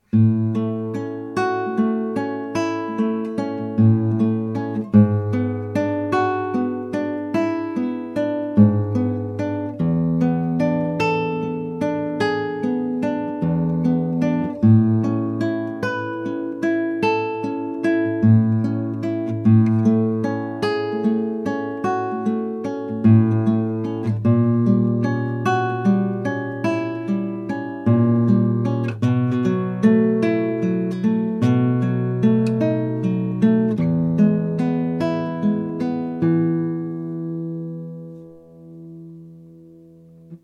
Number 9 is a sweet sounding large sized Classical guitar.
• Engleman Spruce top.
• East Indian Rosewood back and sides
Recorded on a cheap USB microphone onto Audacity on my laptop without any equalization or modification.